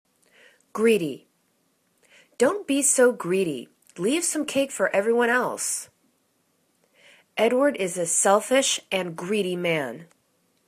greed.y      /'gri:di/    adj